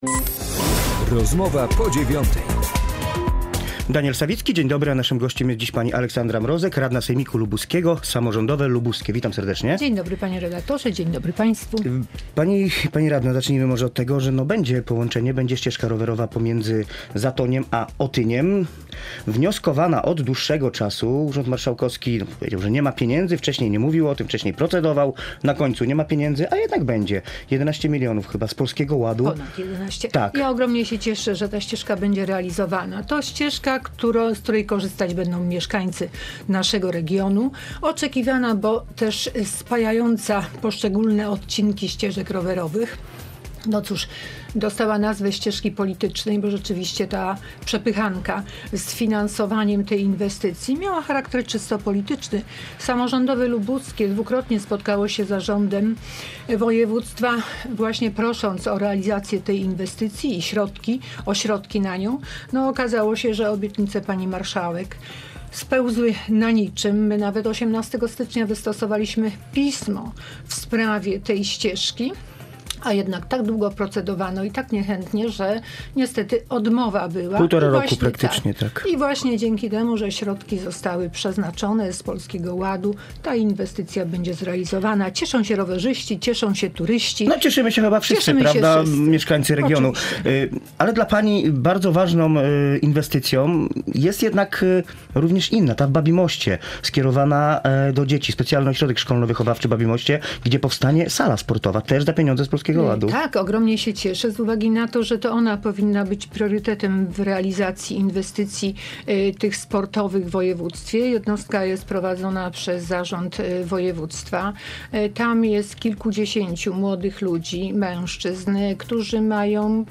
Gościem Rozmowy po 9. była Aleksandra Mrozek, radna Sejmiku Województwa Lubuskiego z klubu Samorządowe Lubuskie: